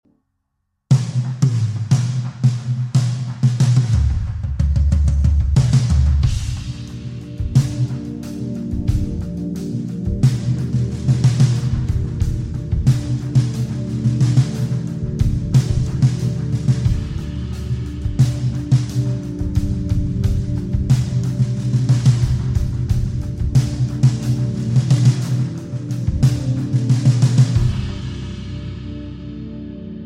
16» Pancake snare from A&F sound effects free download